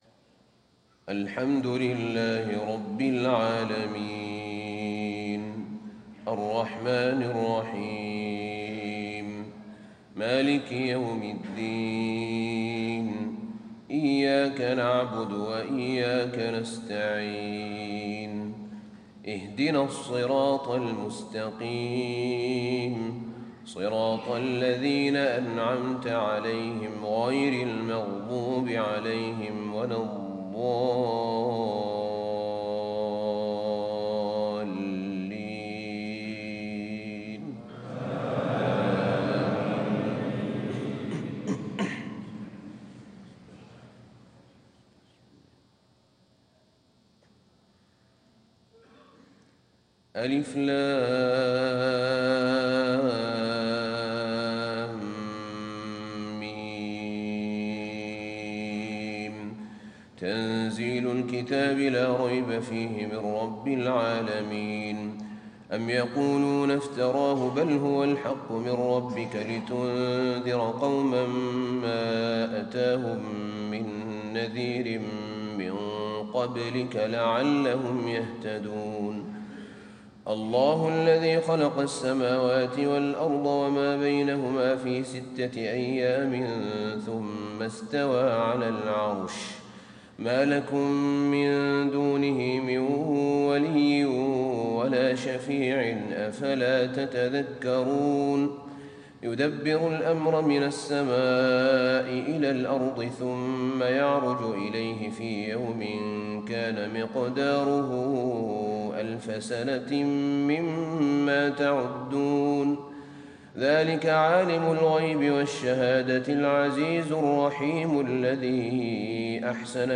صلاة الفجر 1رجب 1437هـ سورتي السجدة و الإنسان > 1437 🕌 > الفروض - تلاوات الحرمين